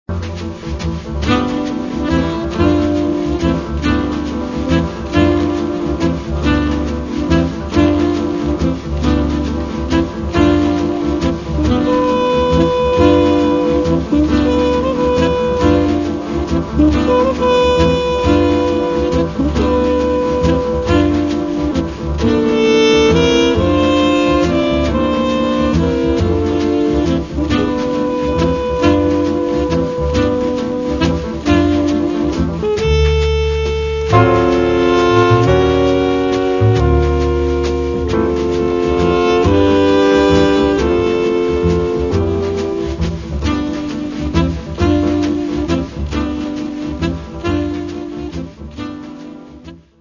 cool jazz
an example of cool modality at work